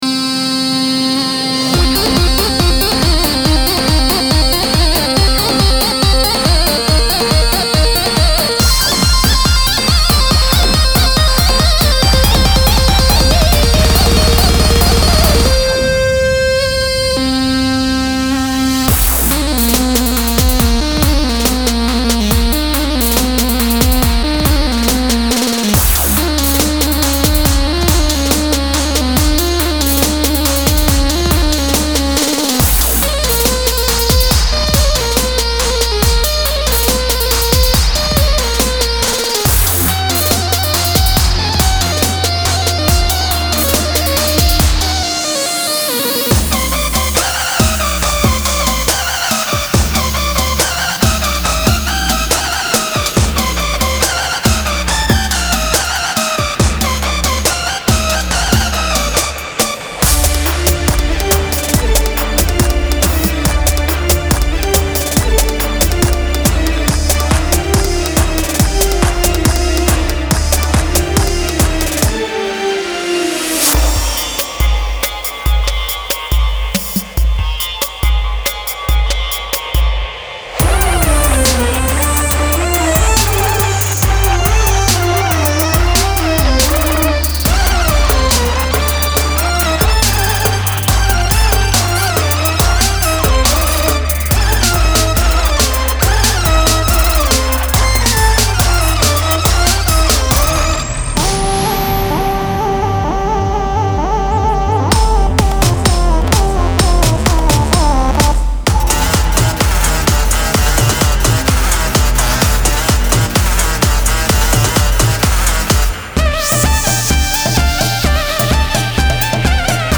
如果您面临将东方声音带入音轨的任务，那么该产品一定会对您有所帮助。
• Tempo – 128,140 BP
• 30 Bass Loops
• 60 Drum Loops
• 30 Percussion Loops
• 40 Synth Lead Long Phrases